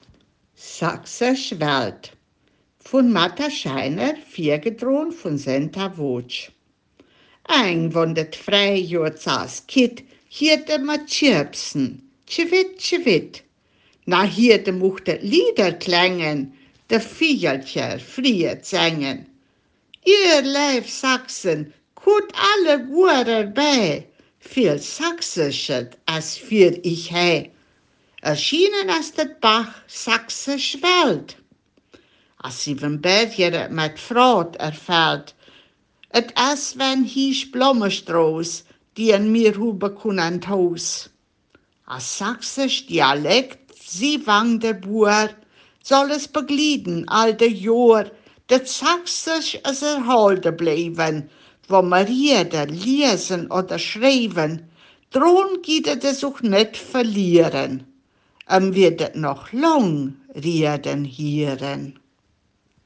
Ortsmundart: Hermannstadt